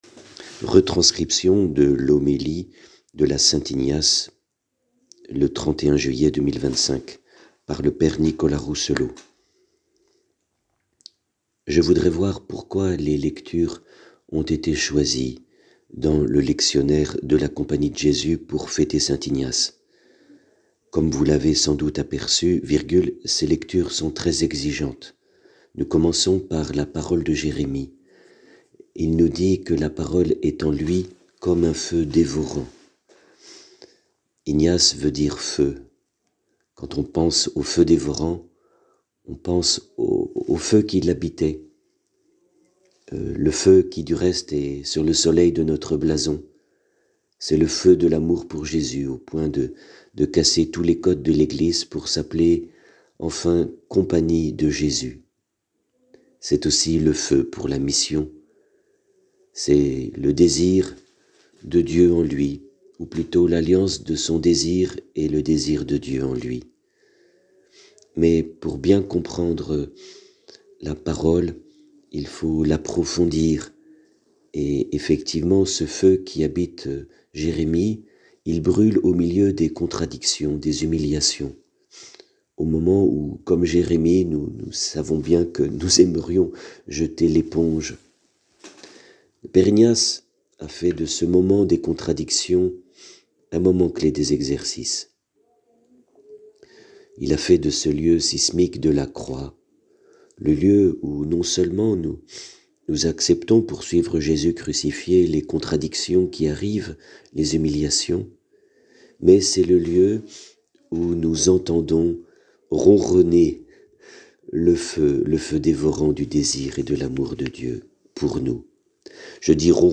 Homélie Fête de St Ignace: Le feu dévorant – Eglise Saint Ignace